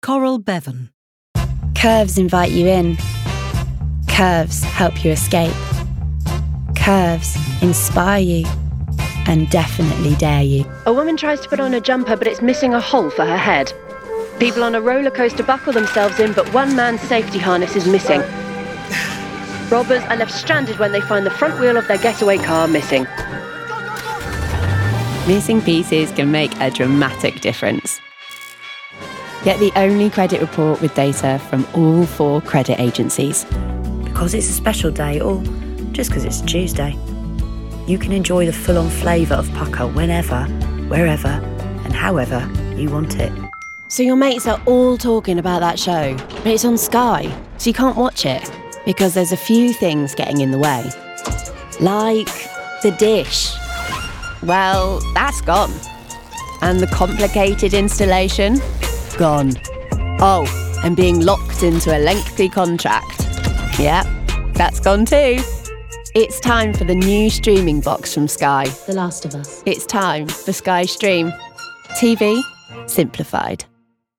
Female Voices
Commercial 0:00 / 0:00